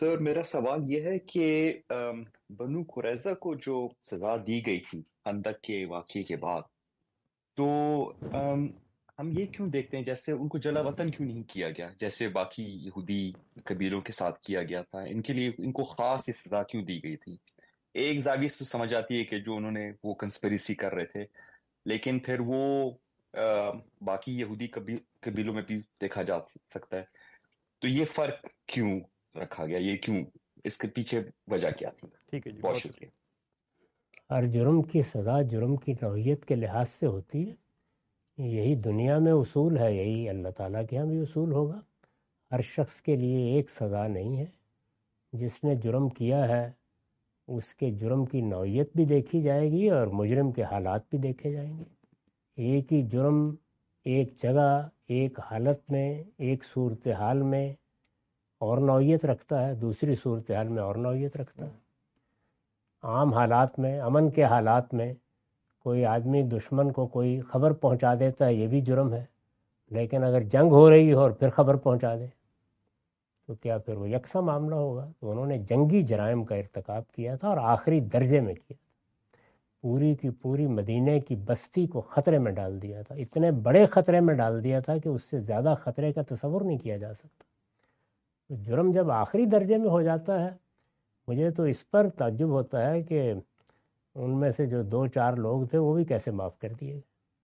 In this video, Mr Ghamidi answer the question about "Why was the harshest punishment given to the Banu Qurayza?".
اس ویڈیو میں جناب جاوید احمد صاحب غامدی "بنو قریظہ کو سخت ترین سزا کیوں دی گئی؟" سے متعلق سوال کا جواب دے رہے ہیں۔